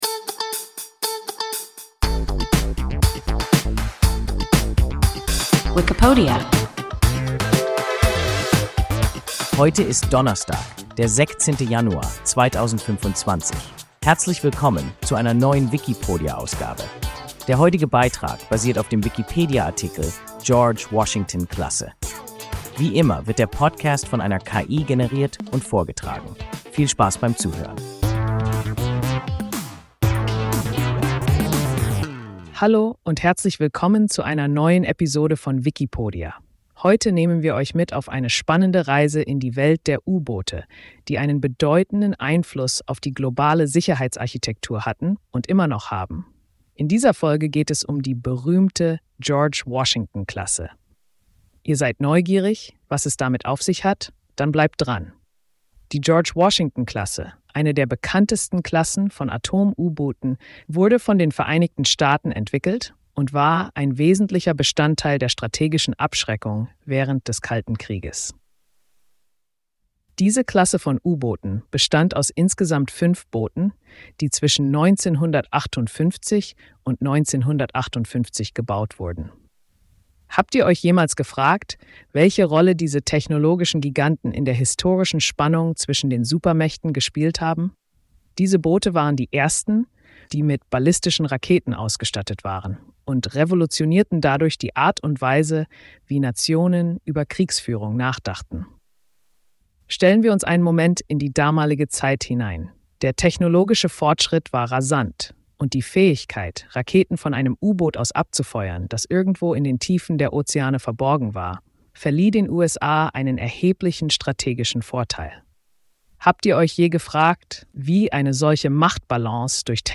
George-Washington-Klasse – WIKIPODIA – ein KI Podcast